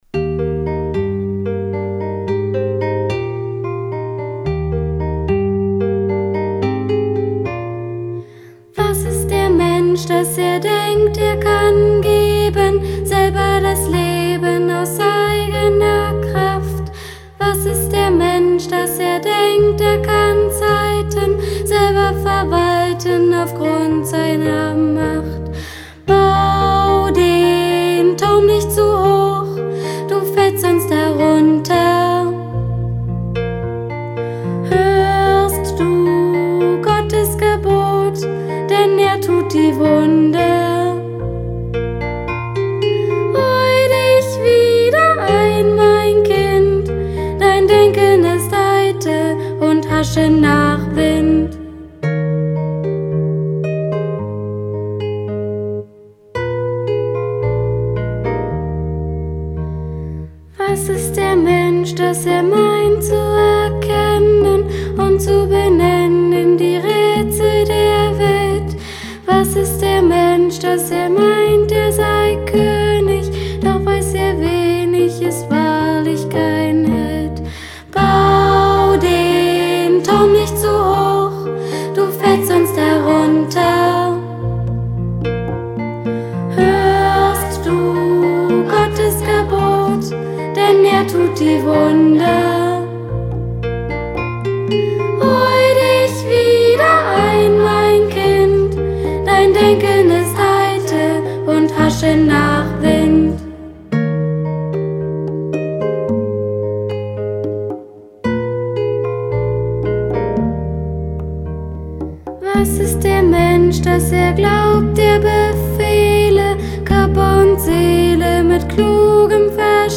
Gemeindelied